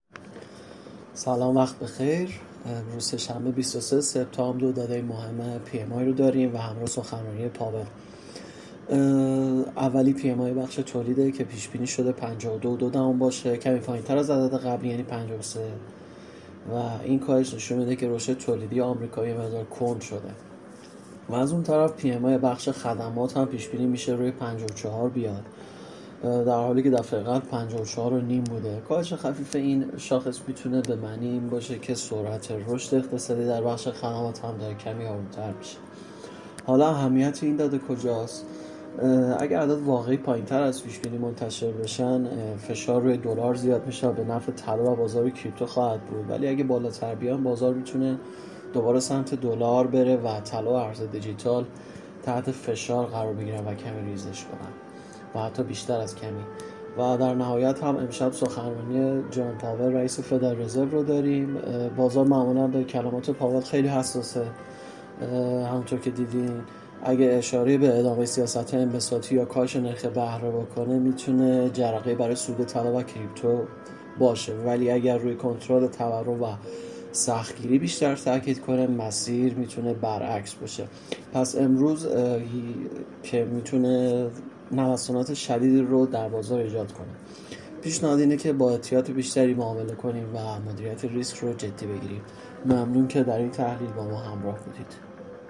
🔸گروه مالی و تحلیلی ایگل با تحلیل‌های صوتی روزانه در خدمت شماست! هدف اصلی این بخش، ارائه تحلیلی جامع و دقیق از مهم‌ترین اخبار اقتصادی و تأثیرات آن‌ها بر بازارهای مالی است.